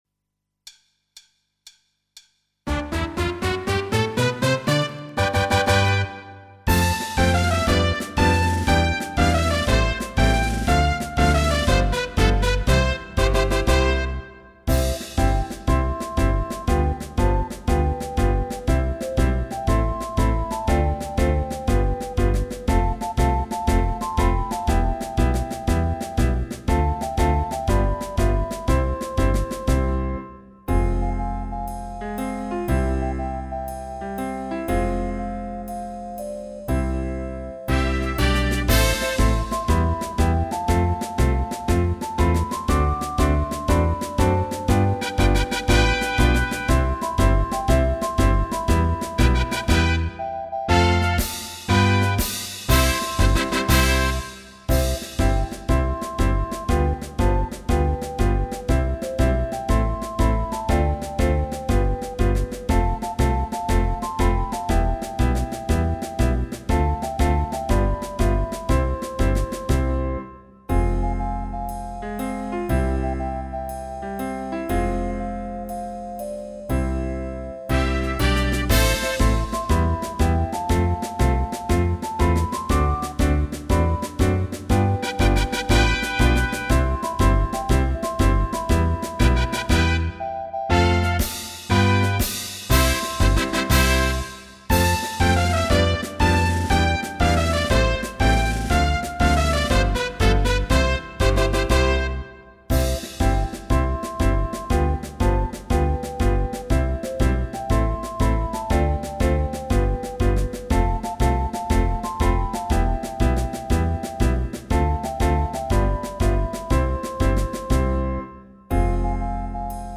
散步 12孔C调 -下载地址列表-乐器学习网